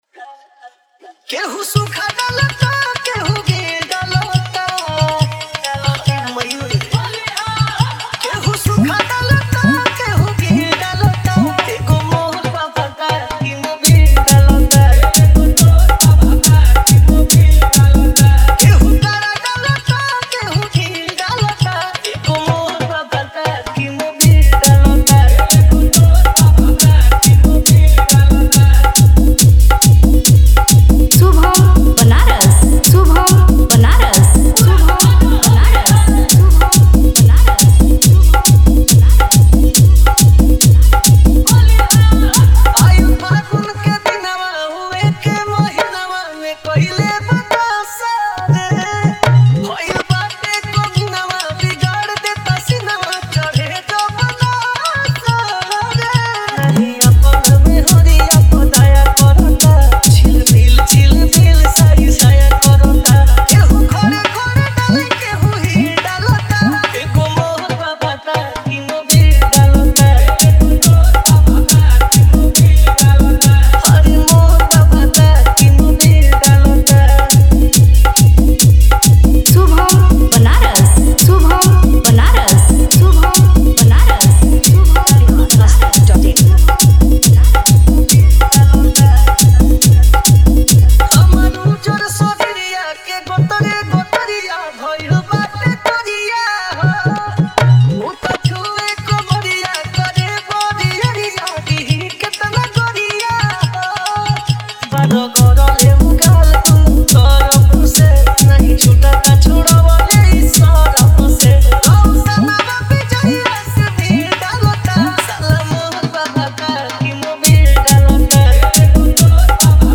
New Bhojpuri Dj Remix